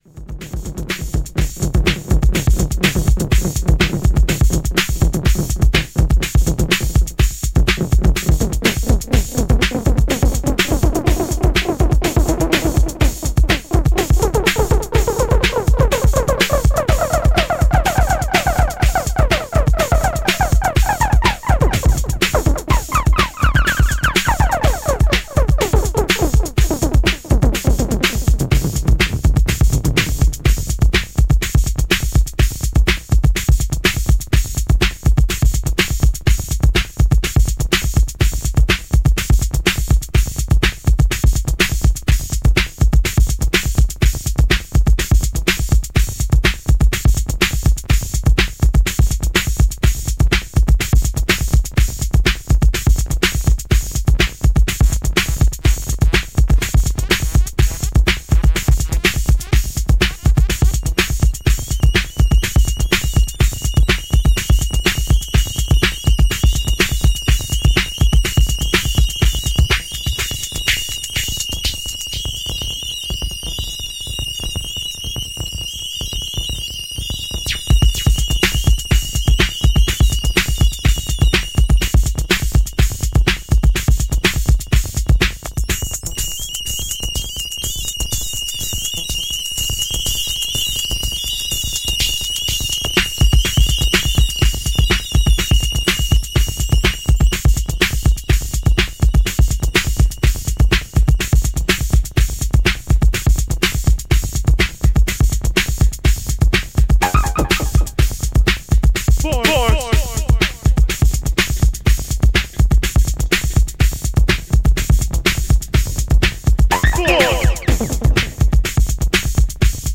サンフランシスコで行われたライヴ・セットからの音源となるようです。